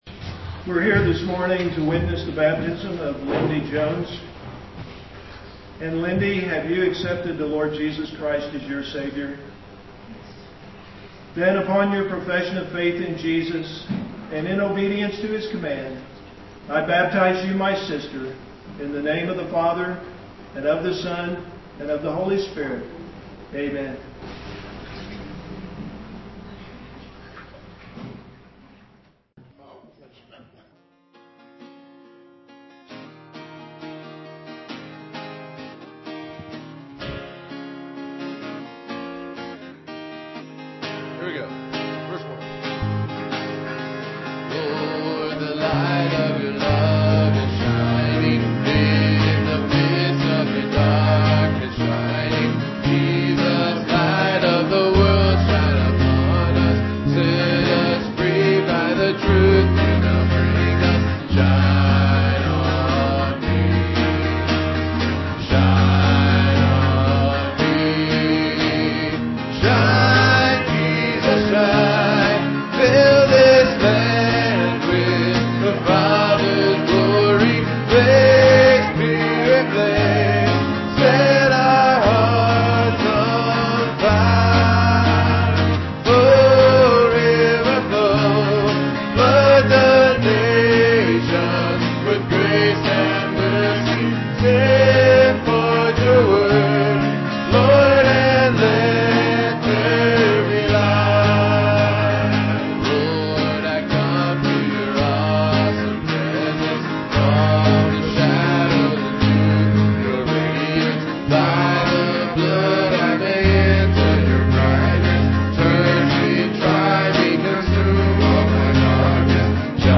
Guitars
vocal solos
Piano and organ duet